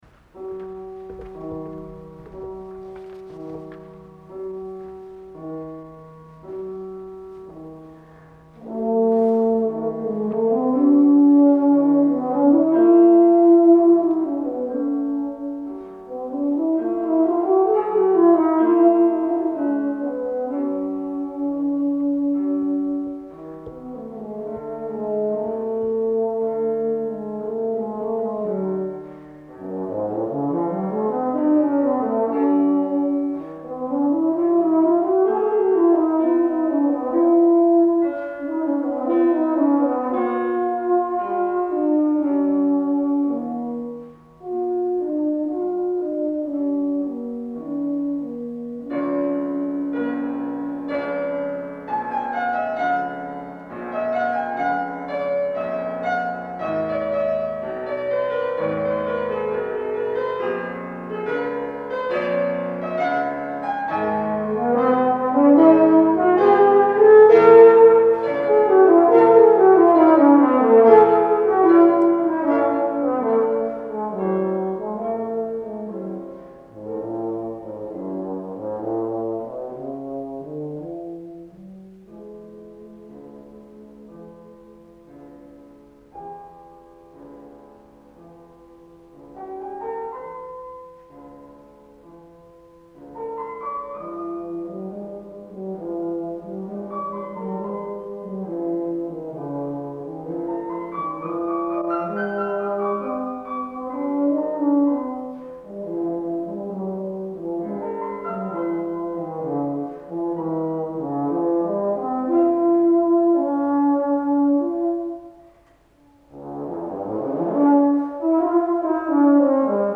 Solist med piano